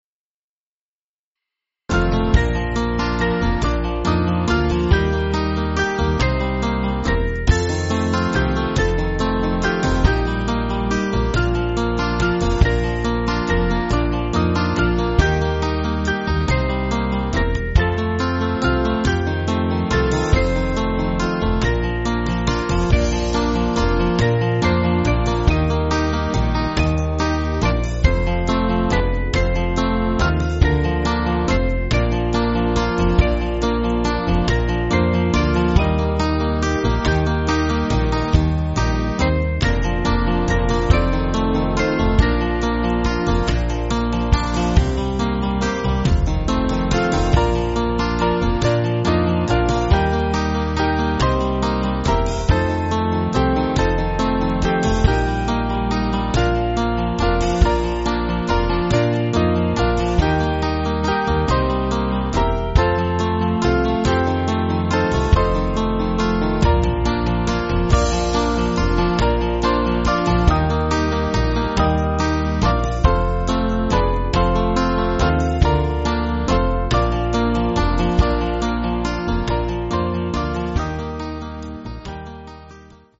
Small Band
(CM)   4/Bb